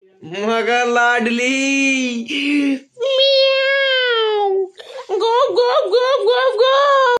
meow gop gop gop gop gop
Memes
meow-gop-gop-gop-gop-gop-02cea55e.mp3